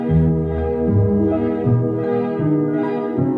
Artifact noise created with noise removal on a tape recordin
I have been trying to digitize my large cassette tape collection and the tape hiss noise removal step is done using Audactiy.
From the very beginning, the right channel will have a very particular noise (sounds like sands dropping on floor), while the left channel is almost perfect.